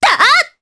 Epis-Vox_Attack2_jp.wav